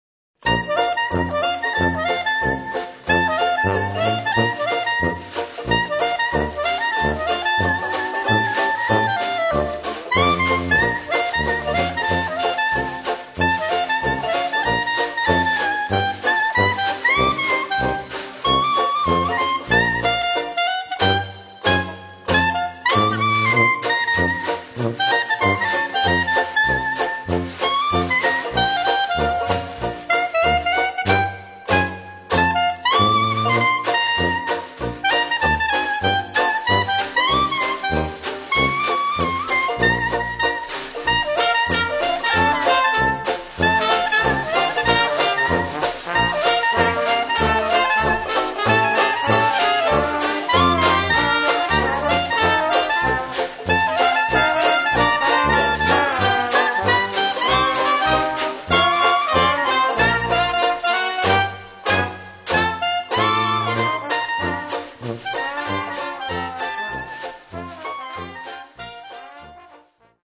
Trompete, Gesang
Posaune
Klarinette
Tenorbanjo
Schlagzeug
Plektrumbanjo, Gitarrenbanjo, Waschbrett
Aufgenommen im Klangstudio LEYH, 69207 Sandhausen